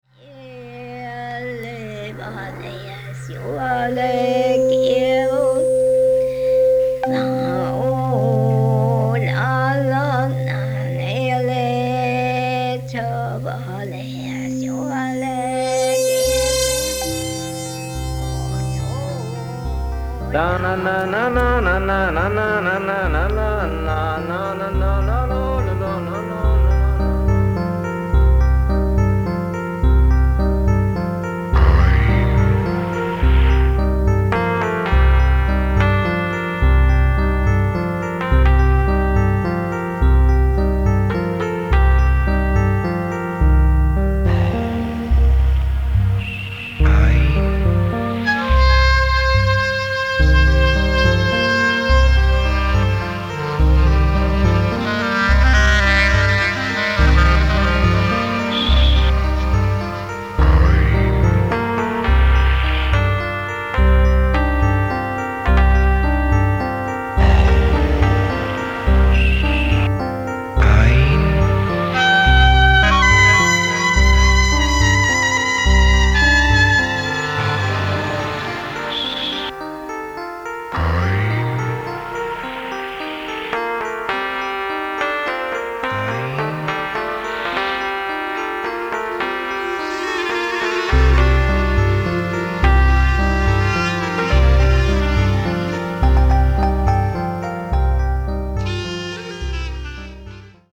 キーワード：宅録　サウンドスケープ　ミニマル　　空想民俗